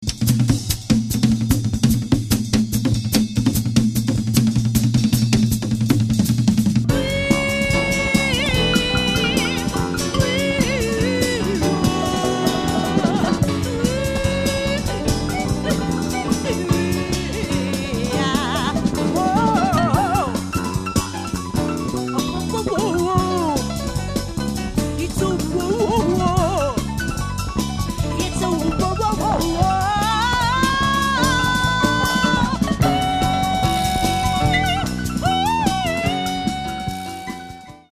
vocals/kazoo
piano/keyboard
tenor sax/flute
drums/percussion